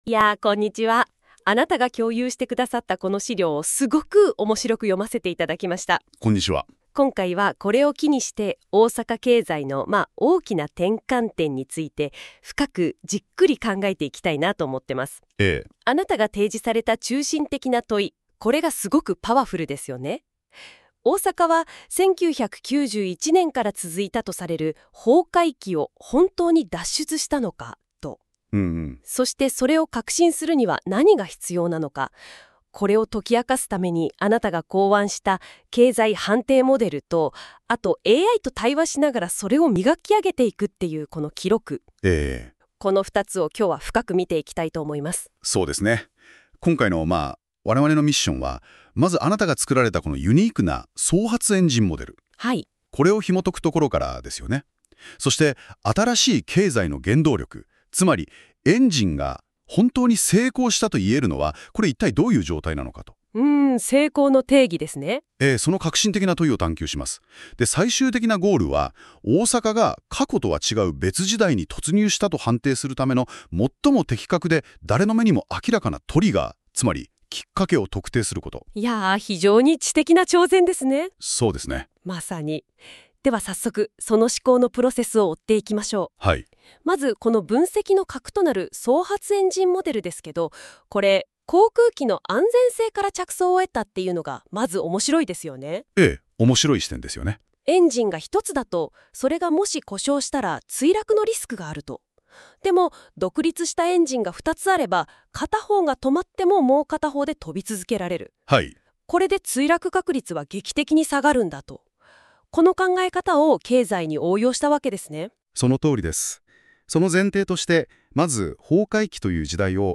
音声解説（NotebookLM作成）